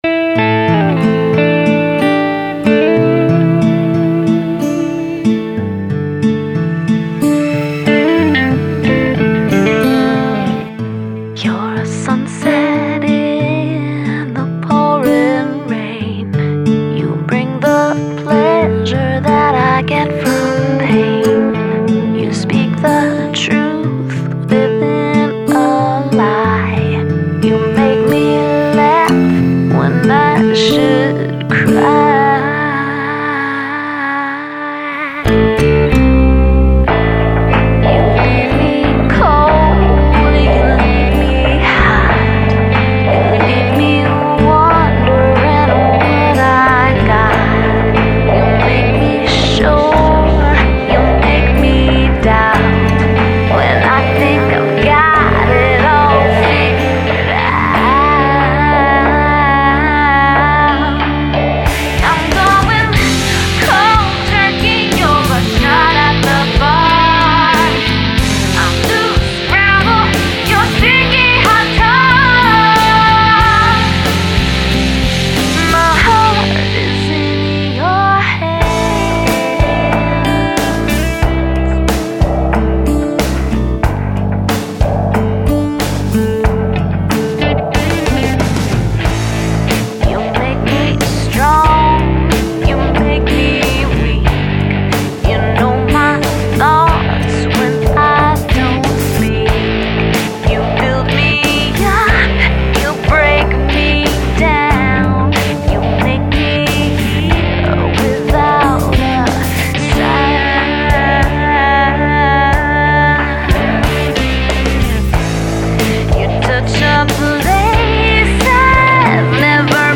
fem Moody